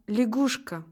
La syllabe accentuée a une prononciation plus longue et plus appuyée que les autres.
лягушка [е][8]